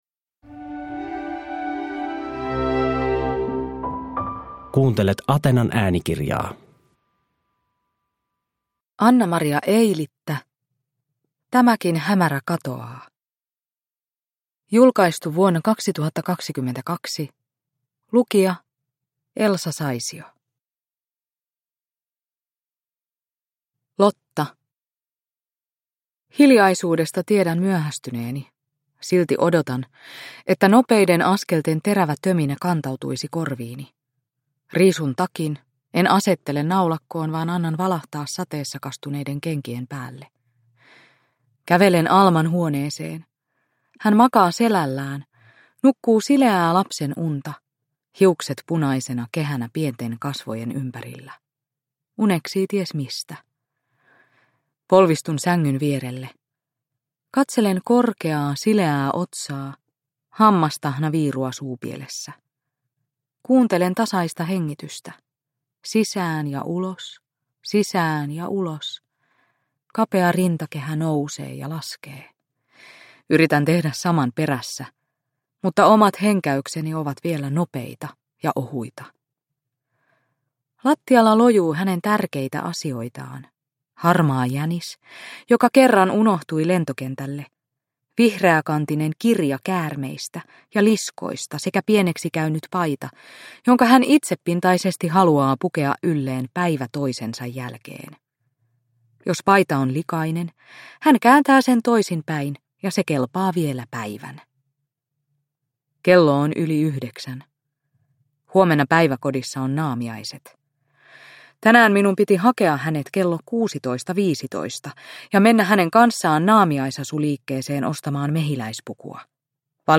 Tämäkin hämärä katoaa – Ljudbok – Laddas ner